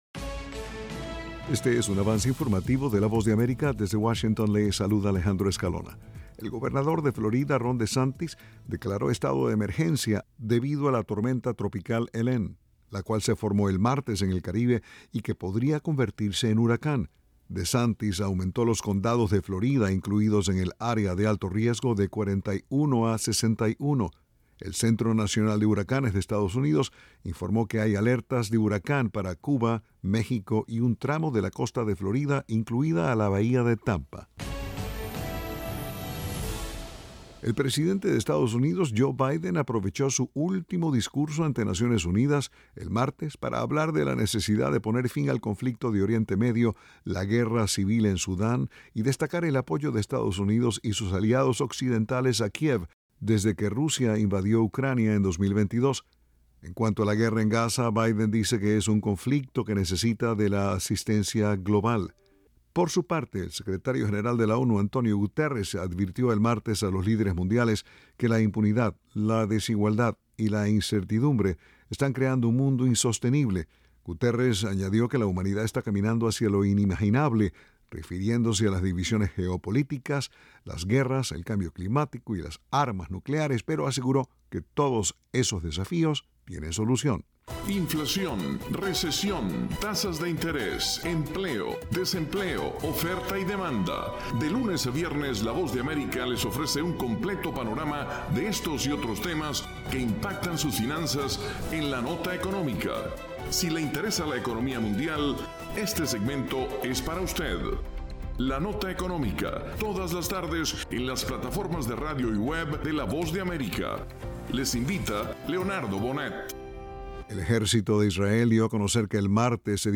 Avance Informativo
Este es un avance informativo presentado por la Voz de América desde Washington.